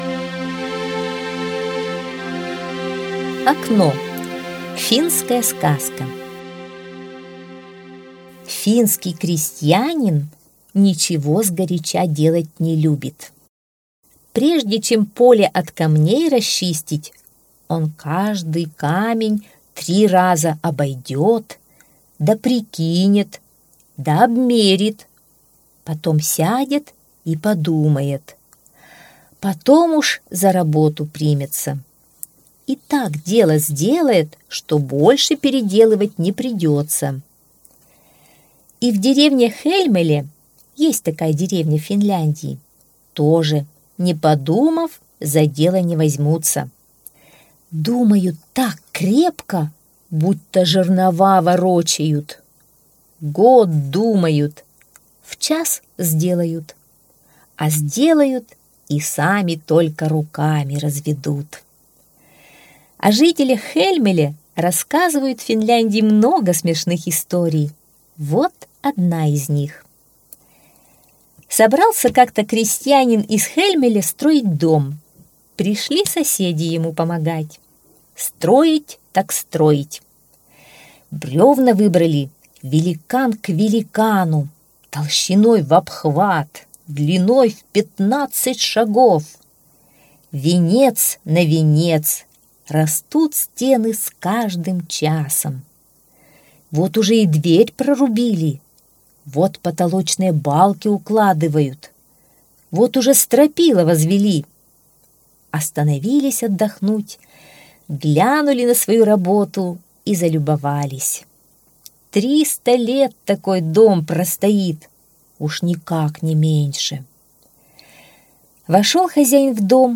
Окно - финская аудиосказка - слушать онлайн